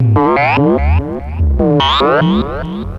MOOG_SCRATCHES_0004.wav